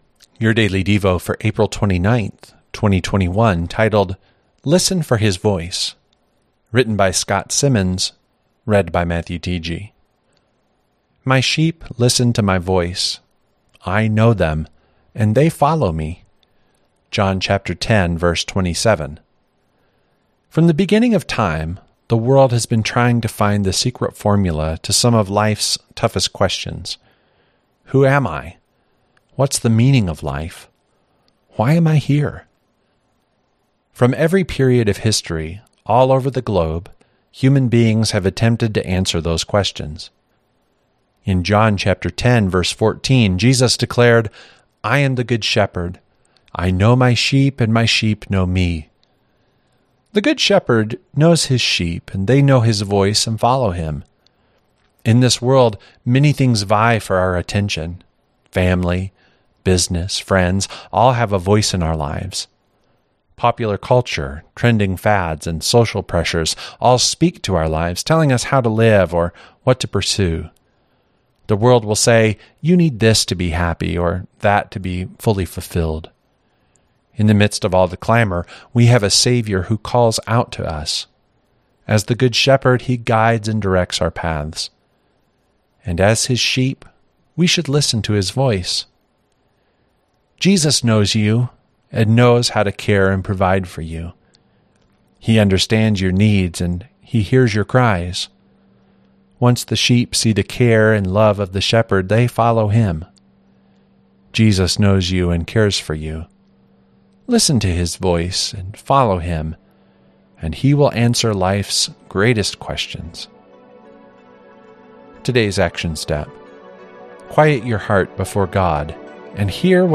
Daily Devotionals